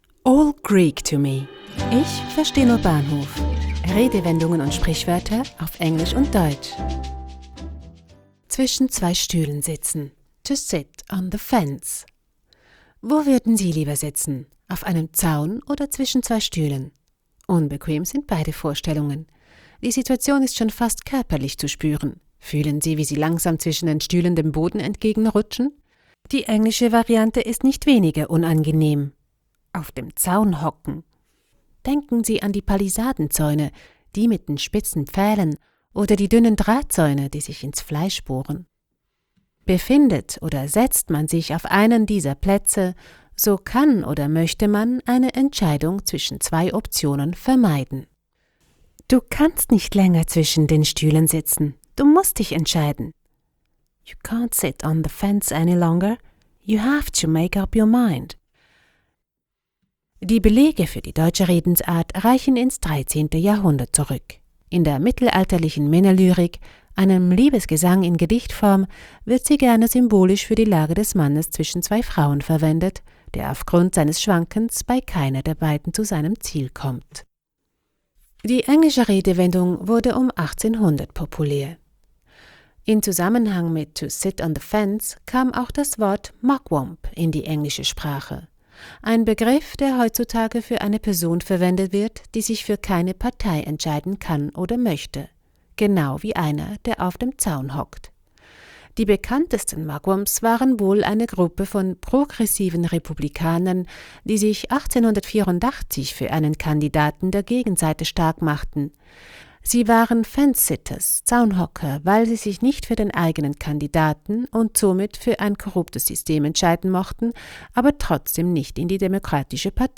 Sprecherin